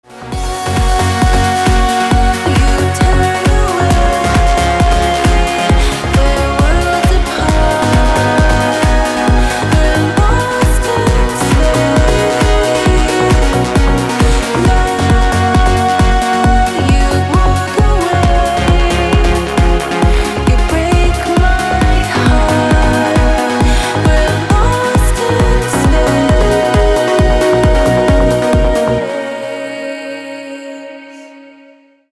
• Качество: 320, Stereo
громкие
женский вокал
dance
Electronic
EDM
Trance
vocal trance